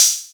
VEC3 Cymbals HH Open 055.wav